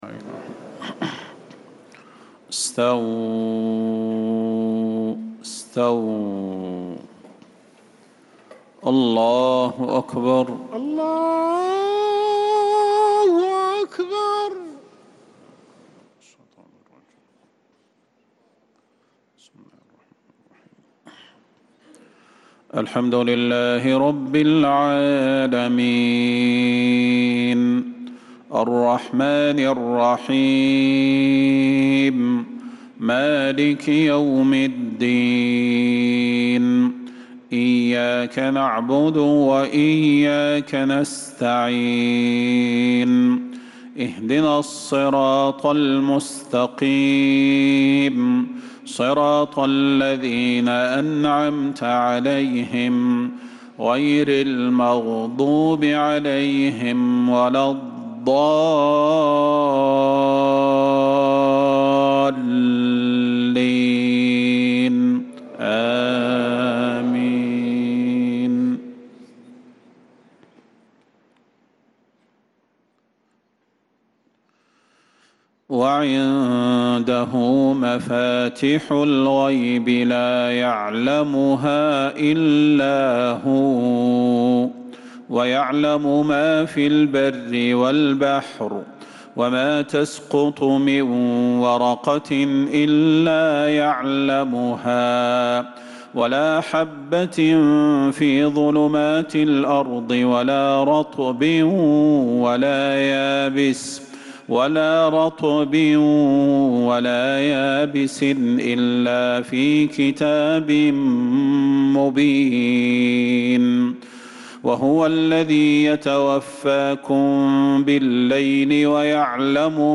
عشاء الثلاثاء 13 محرم 1447هـ من سورة الأنعام 59-62 | Isha prayer from Surah Al-An’aam 8-7-2025 > 1447 🕌 > الفروض - تلاوات الحرمين